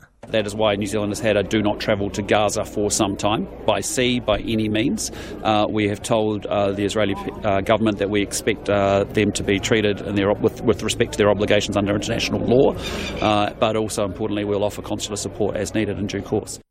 New Zealand Prime Minister Christopher Luxon speaks on the piratical kidnapping of three New Zealand citizens on the high seas by Israel.